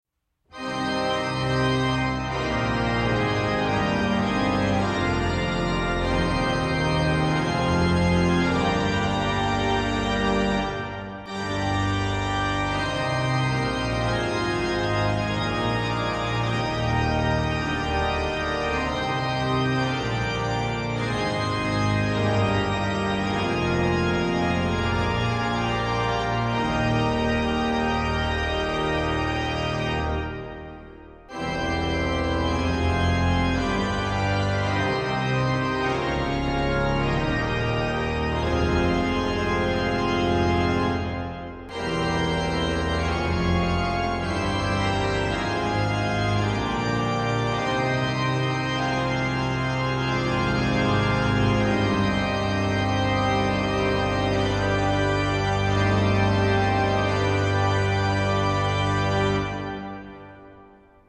Orgelkonzert auf der Wildenburg
An der historischen Orgel in der Schlosskirche
So kam es, dass das Orgelkonzert in einer gut gefüllten Kirche stattfand - zum Lobe Gottes und zur Freude der Menschen.